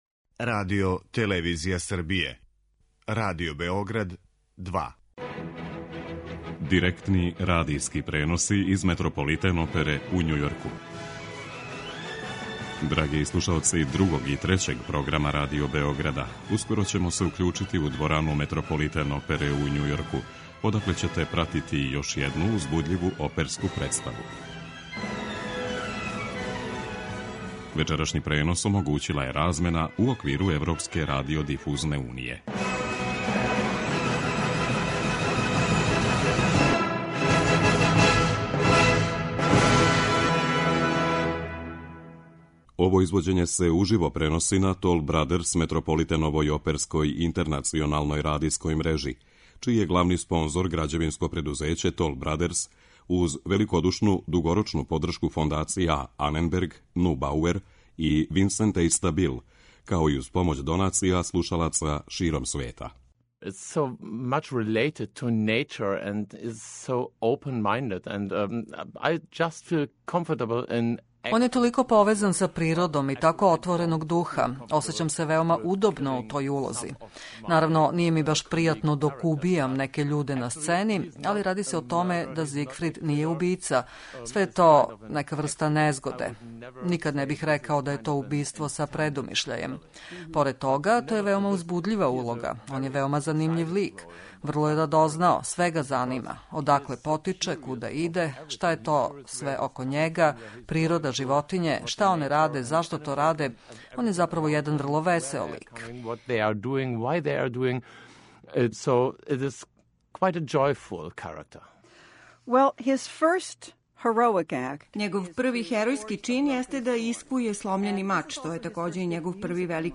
Имаћемо прилике да чујемо шта је о поезији, статусу песника и својој основној уметничкој инспирацији говорио сам песник Стеван Раичковић.